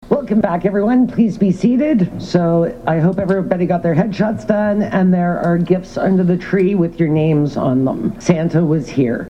The County of Renfrew took care of its constitutional business at the Upper Tier’s Inaugural meeting this morning, and, when the session resumed following the lunch break, it was a familiar voice that called proceeding to order.
dec-10-jennifer-muephy-call-to-order-1.mp3